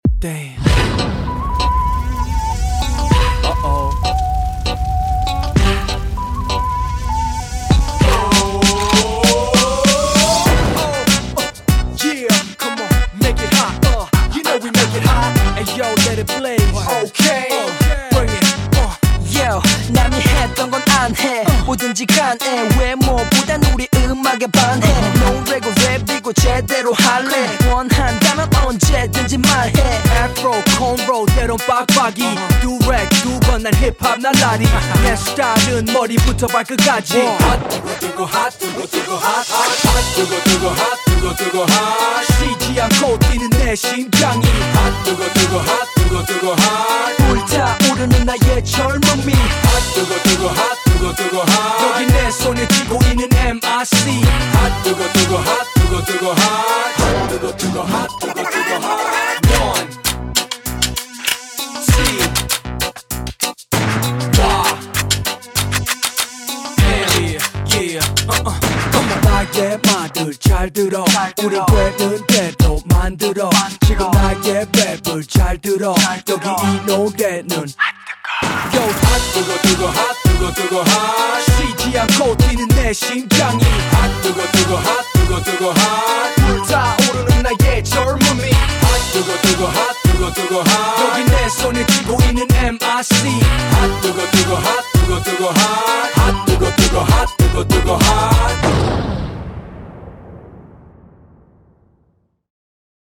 BPM98
Audio QualityPerfect (High Quality)
Old school Korean hip-hop music